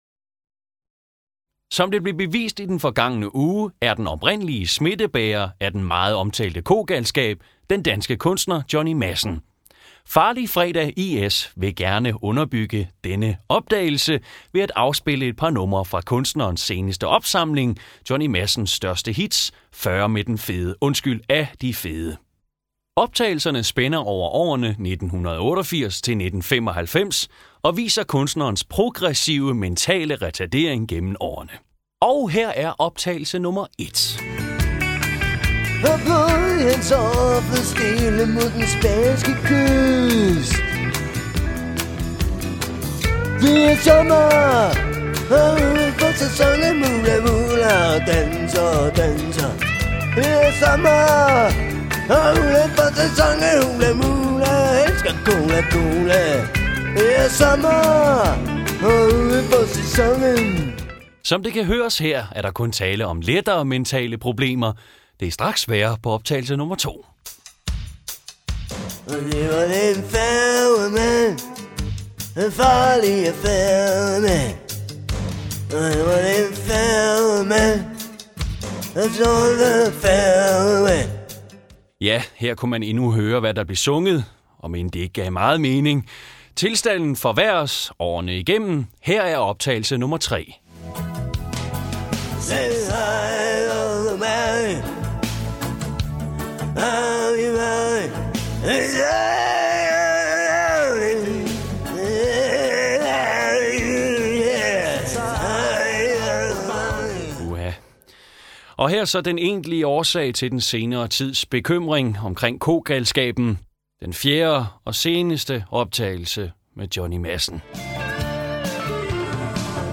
Det er her, du kan høre alle de gode, gamle indslag fra ANR's legendariske satireprogram.